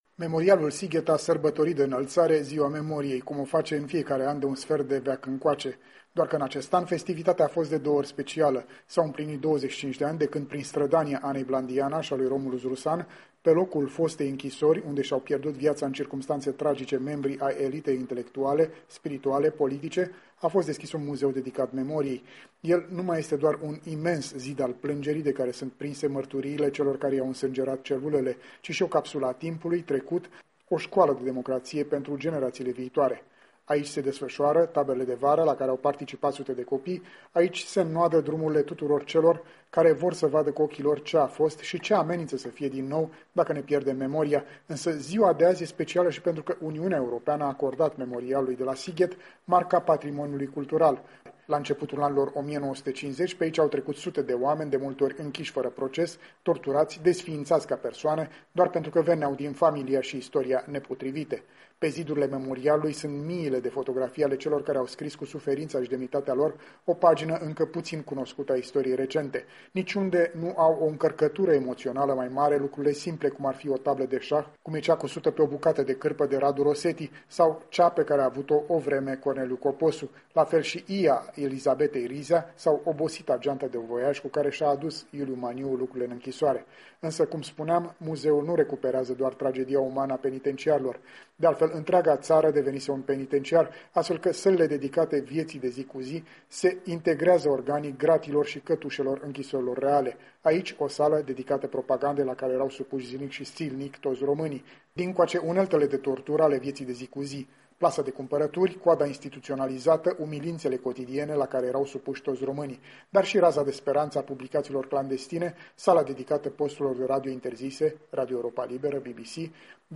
În direct de la aniversarea de 25 de ani a Memorialului de la Sighet